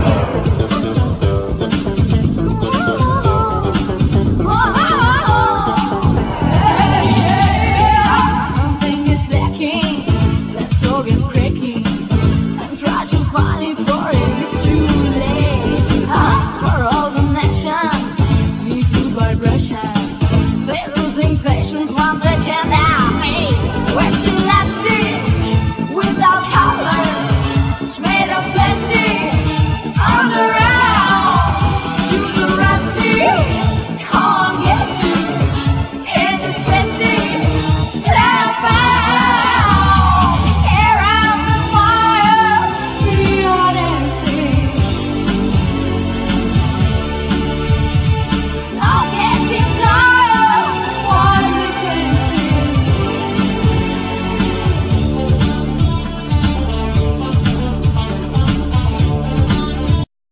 Guiats, Synthsizers, Drum machine, Vocal
Bass
Drums
Double bass
Piano, Synthesizers
Chorus